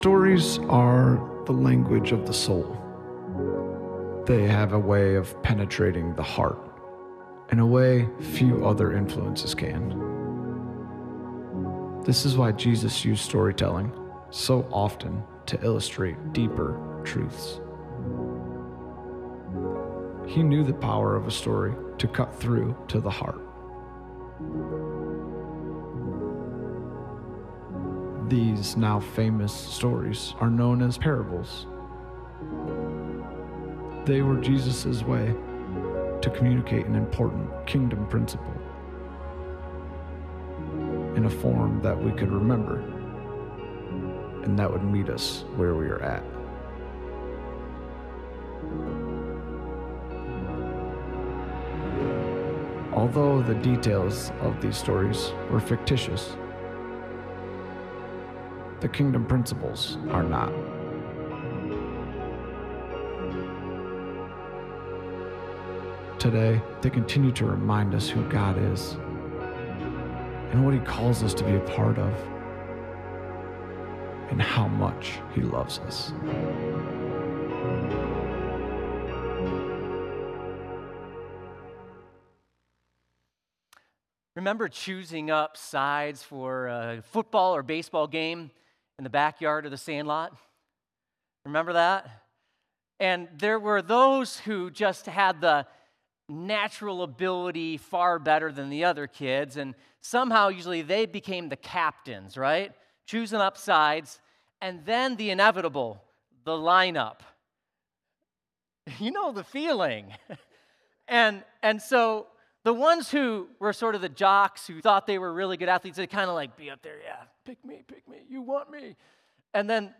Sermon-Audio-August-7-2022.m4a